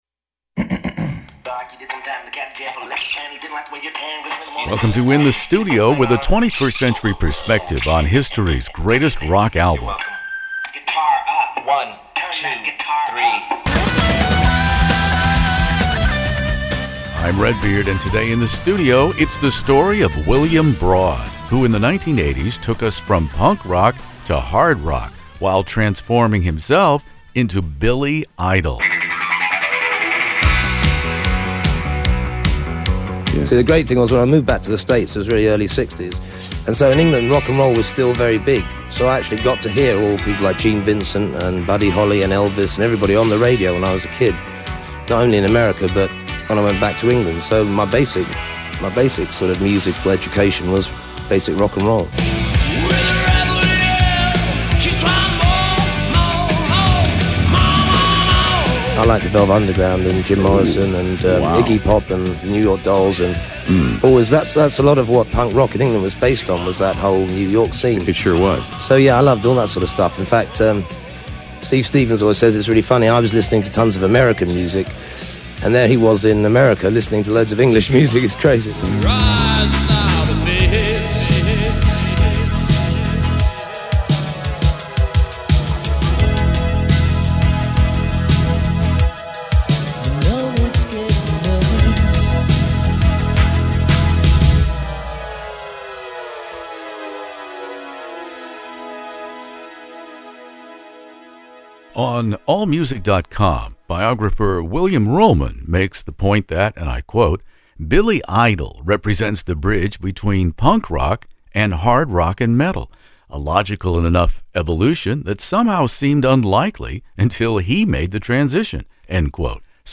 Billy Idol joins me In the Studio on the fortieth anniversary of Rebel Yell to invoke the eleventh commandment: thou shalt have no idols before Billy!